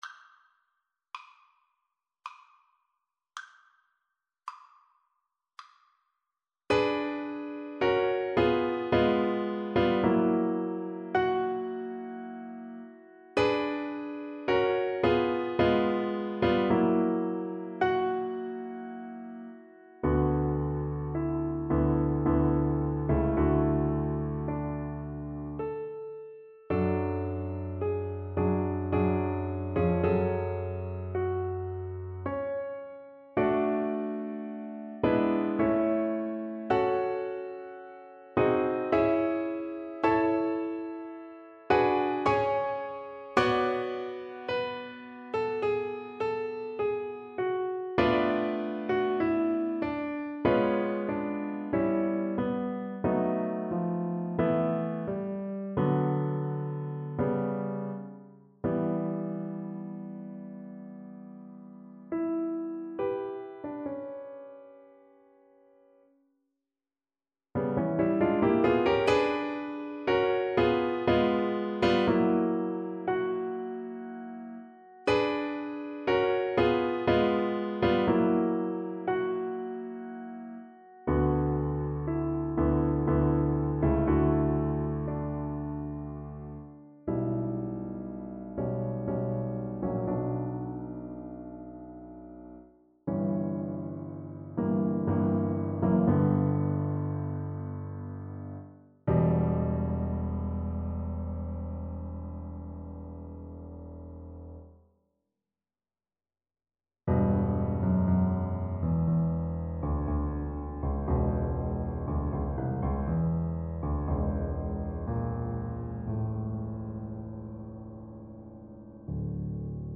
Violin version
3/4 (View more 3/4 Music)
Adagio lamentoso = 54
Classical (View more Classical Violin Music)